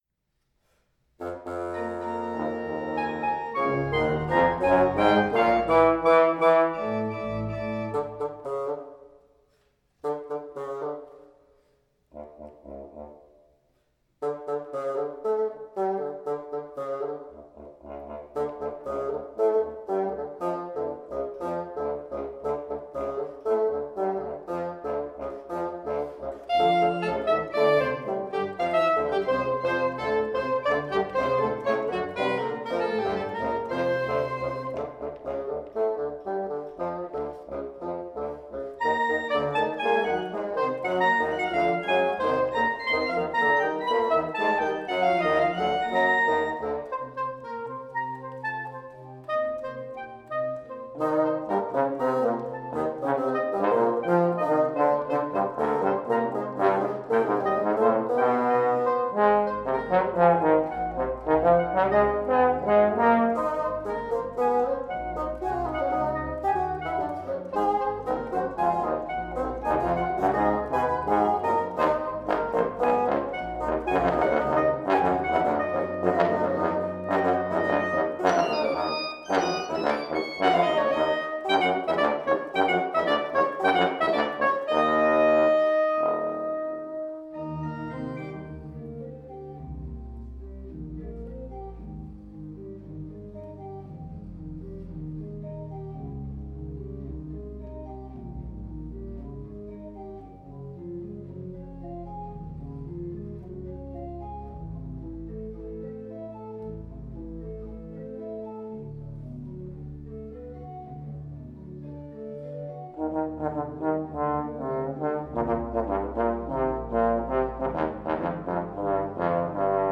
Breath Dance Sax
Breath Dance Bassoon
Breath Dance Trombone
Breath Dance organ part